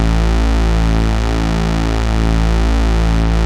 HARD SQUARE1.wav